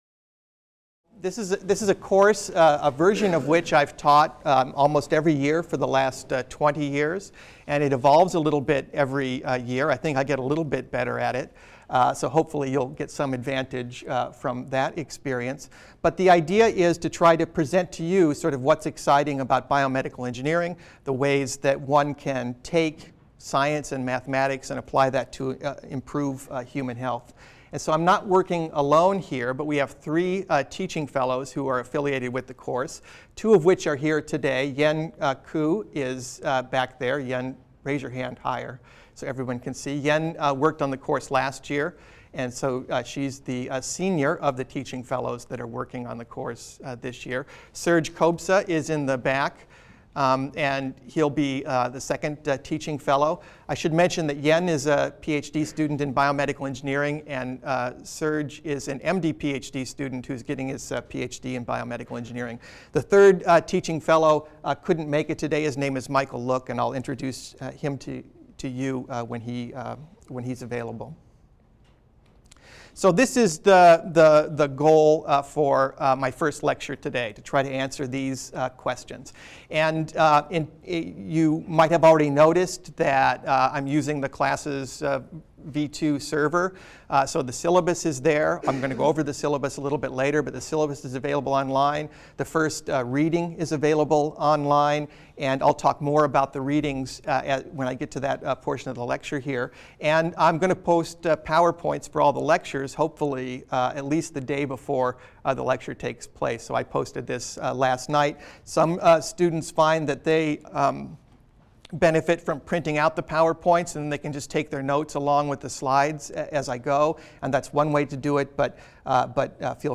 BENG 100 - Lecture 1 - What Is Biomedical Engineering? | Open Yale Courses